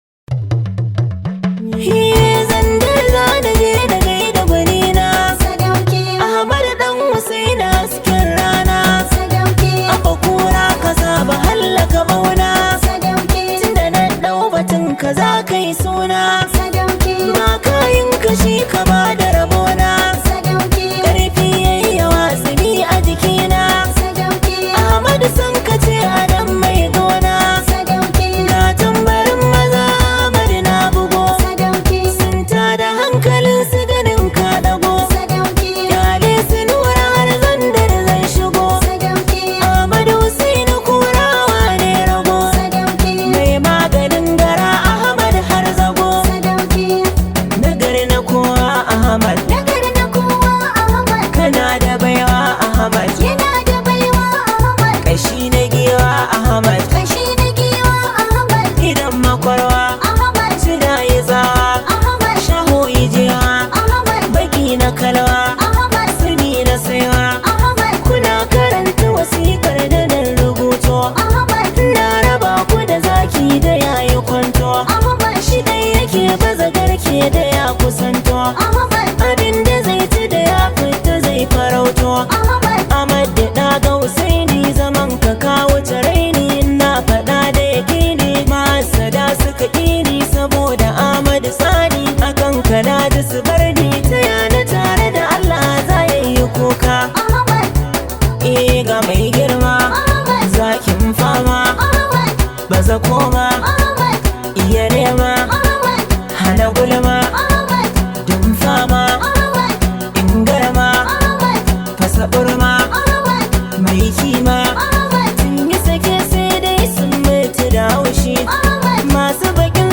Hausa Music